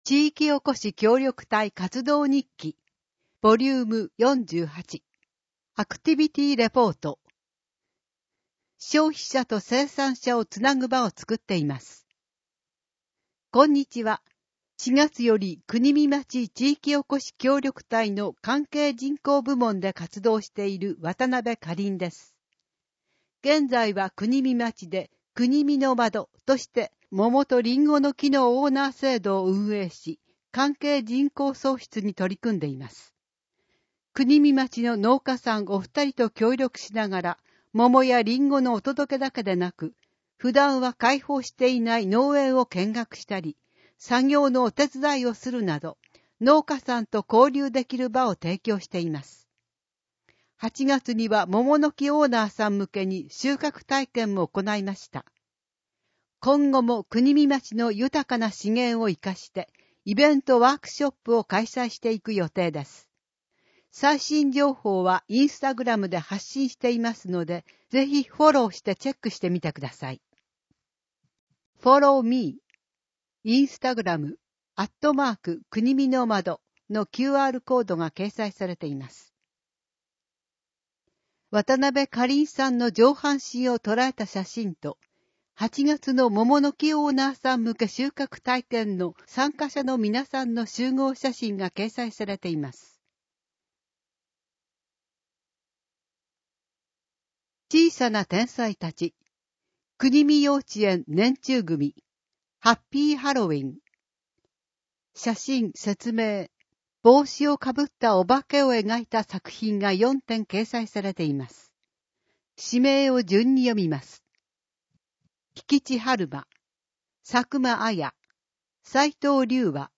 ＜外部リンク＞ 声の広報 声の広報(1) [その他のファイル／15.04MB] 声の広報(2) [その他のファイル／15.26MB]